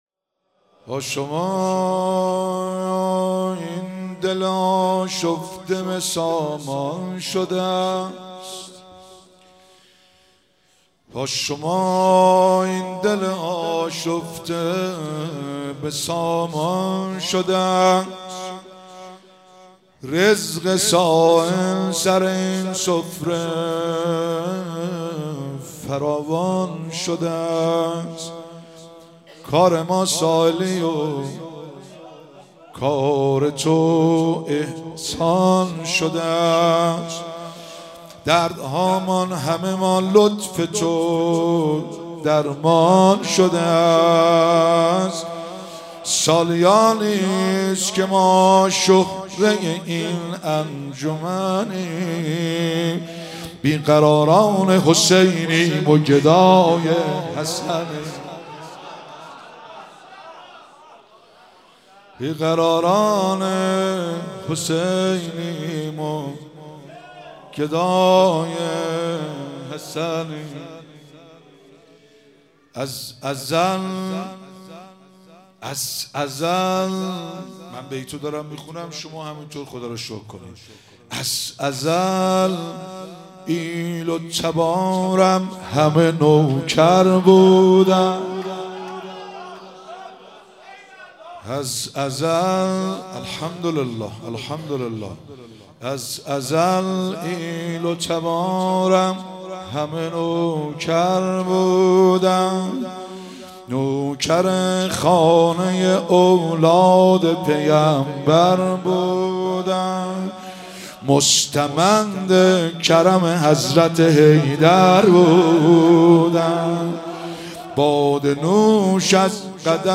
مراسم مناجات خوانی شب پانزدهم و جشن ولادت امام حسن مجتبی علیه السلام ماه رمضان 1444